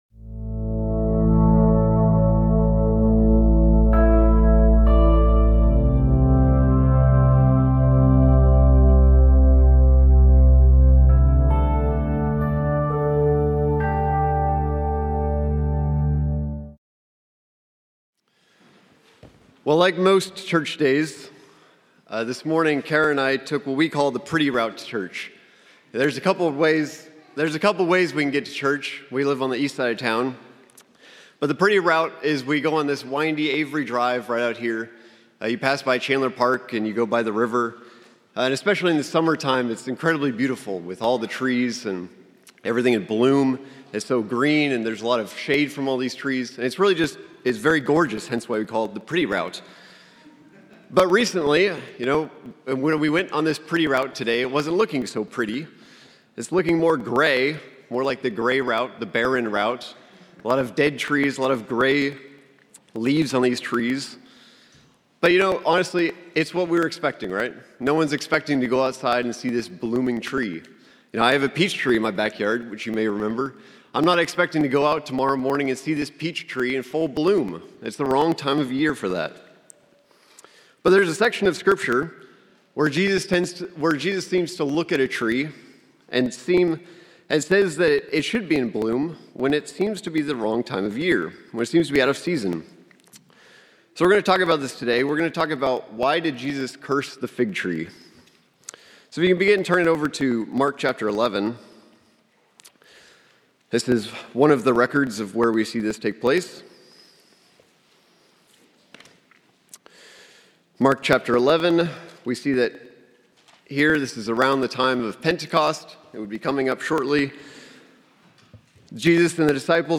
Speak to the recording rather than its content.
Given in Tulsa, OK Oklahoma City, OK